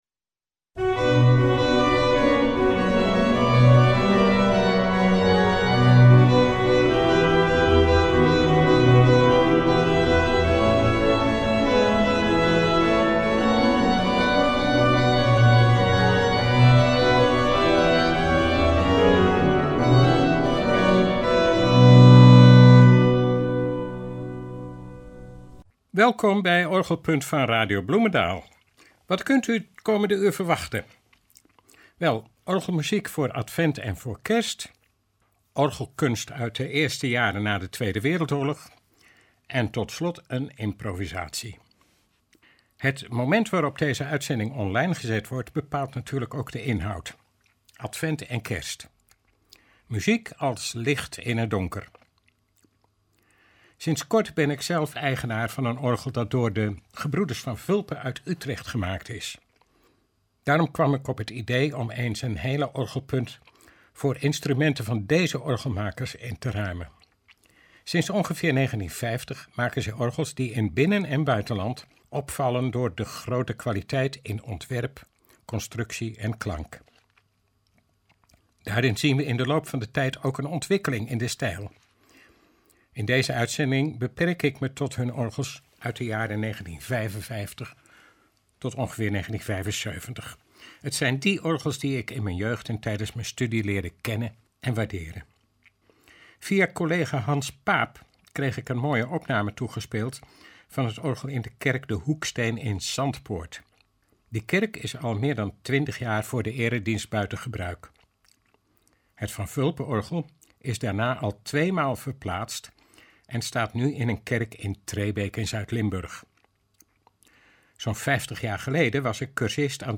Daarbij klinken uitsluitend orgels die gemaakt of gerestaureerd zijn door de orgelmakers Gebroeders Van Vulpen uit Utrecht, juist nu kort geleden bekend werd dat zij gaan fuseren met de firma Elbertse uit Soest.
Het kleine historische orgel van de kerk in Overlangbroek behoort tot de mooiste van de provincie Utrecht.